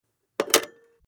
Rotary Phone 02
Rotary_phone_02.mp3